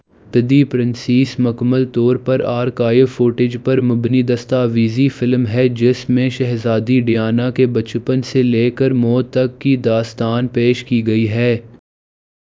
Spoofed_TTS/Speaker_06/273.wav · CSALT/deepfake_detection_dataset_urdu at main